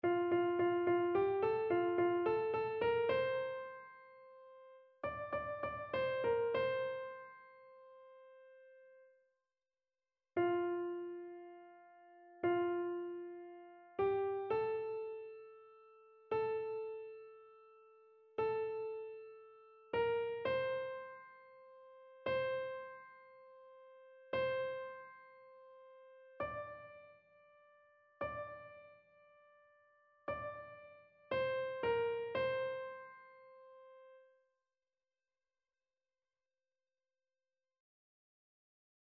annee-abc-temps-de-noel-nativite-du-seigneur-psaume-96-soprano.mp3